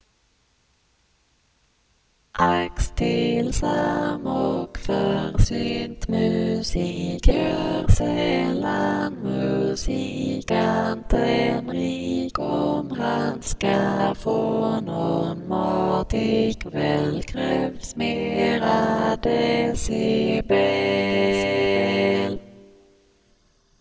Homophonous, four voices Download